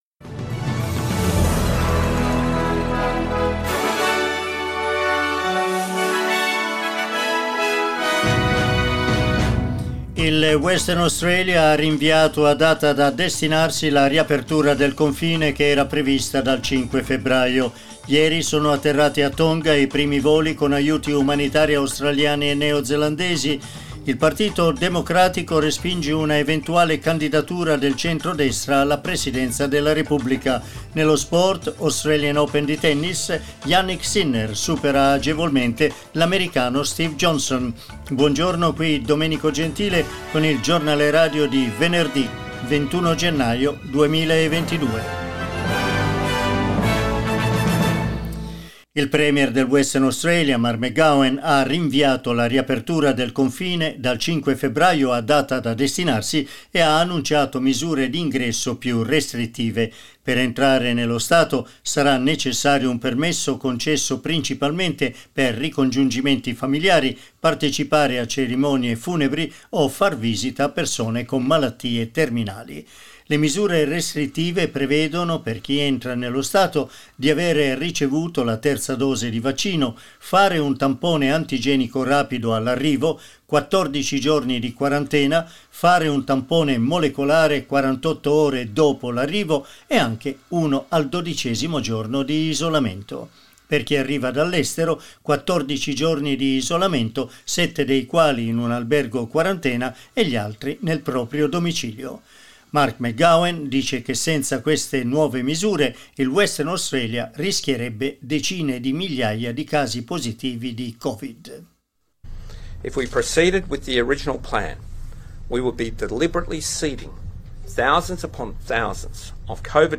Giornale radio venerdì 21 gennaio 2022
Il notiziario di SBS in italiano.